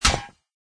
metalstone2.mp3